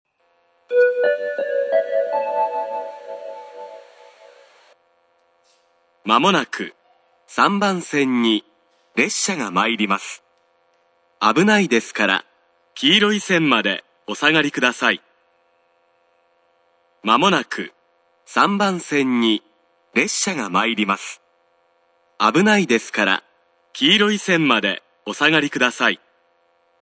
スピーカー：小VOSS
音質：A
接近放送（上り線）　(131KB/26秒)